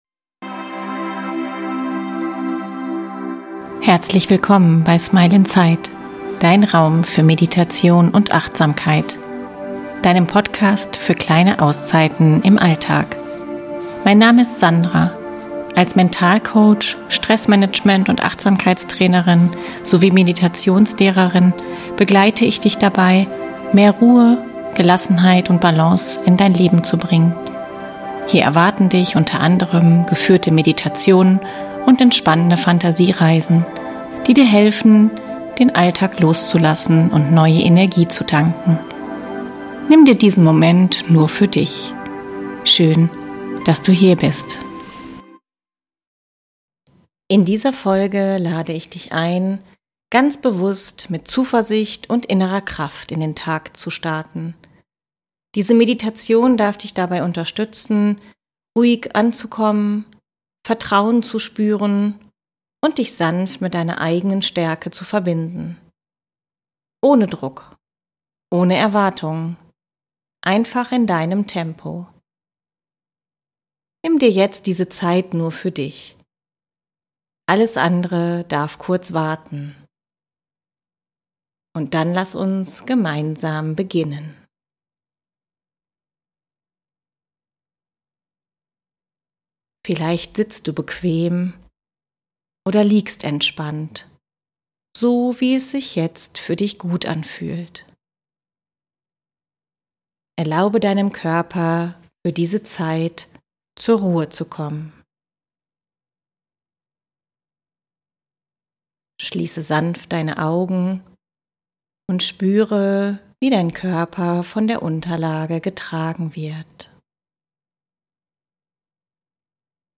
Gemeinsam nehmen wir uns Zeit, bewusst anzukommen, den Atem zu spüren und unser Herz für Hoffnung, Vertrauen und innere Stärke zu öffnen. Du wirst sanft durch eine geführte Meditation geleitet, in der du innere Ruhe findest, positive Affirmationen hörst und ein inneres Lächeln entstehen lässt, das dich durch den Tag begleitet.